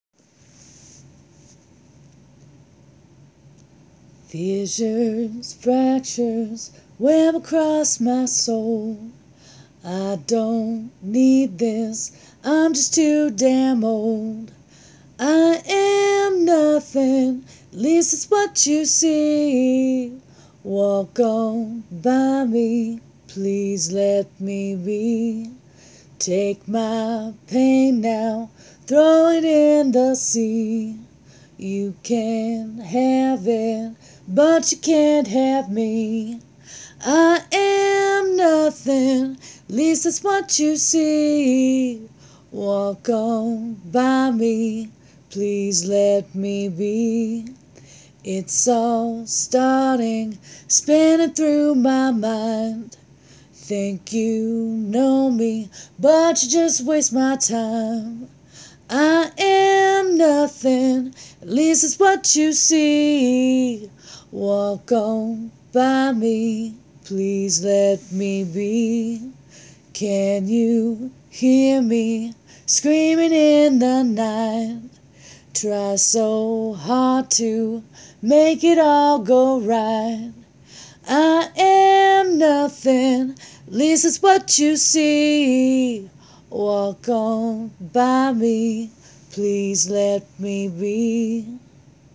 I wrote this several years ago with a bad head cold and recorded it a capella on my tablet right then so I didn't loose the melody. It's rough, but I hope you like it.